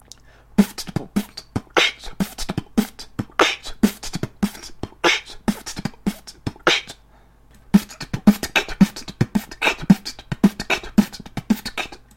В итоге первый хип-хоп, второй днб.
poooff.mp3